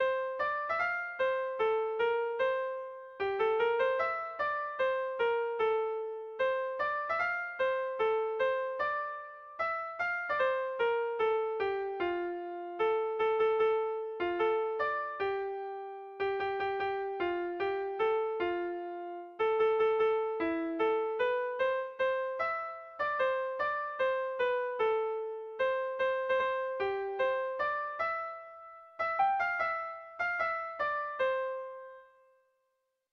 Erlijiozkoa
ABDEDF..